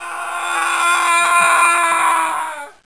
screamingMan.wav